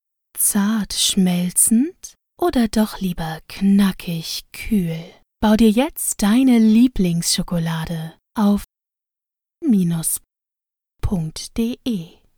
Radio-Spot Süßwaren Startup:
Stimmalter: ca. 18-35 Jahre
Radio-Spot-Suesswaren-Startup.mp3